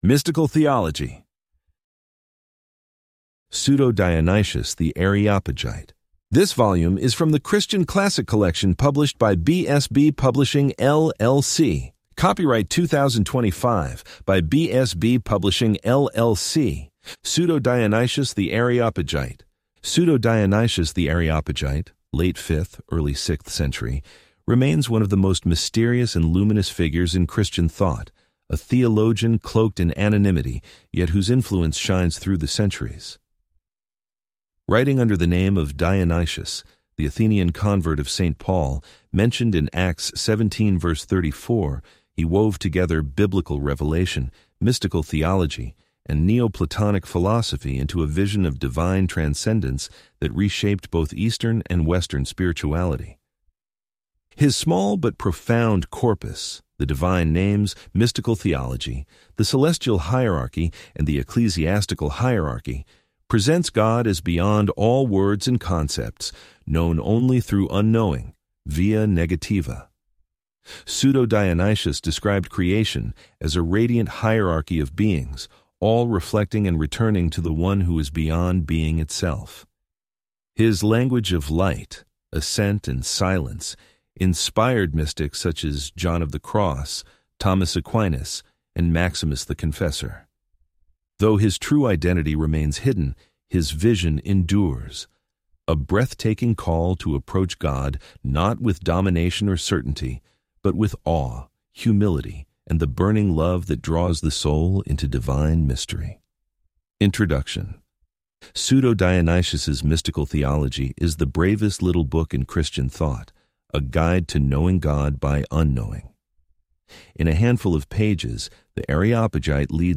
Complete Audiobook Play Download Individual Sections Listening Tips Download the MP3 files and play them using the default audio player on your phone or computer.